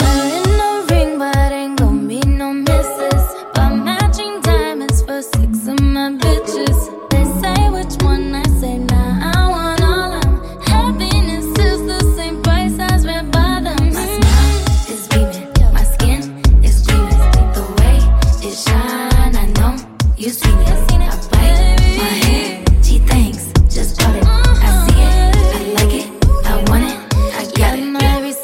Genere: pop, latin pop, remix